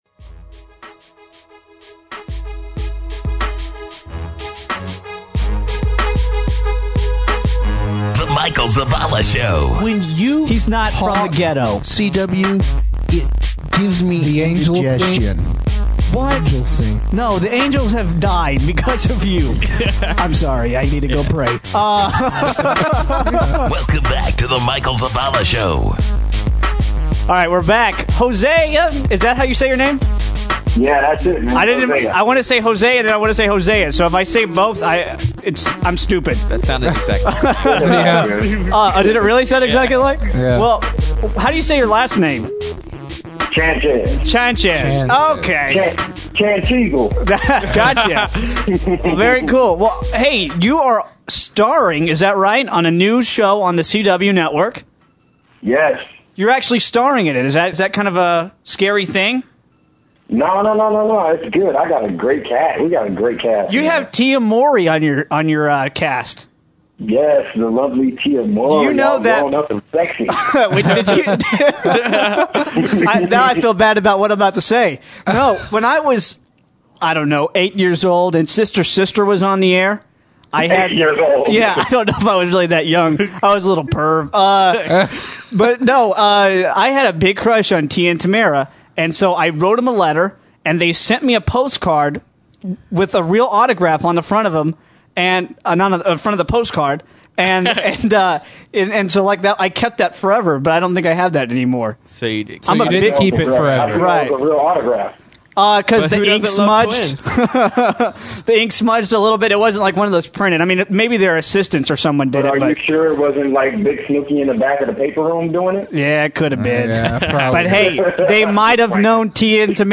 He talked about his upcoming role on The CW new comedy show 'The Game' and what it has been like working with 'Sister, Sister' star Tia Mowry. Listen to the interview click here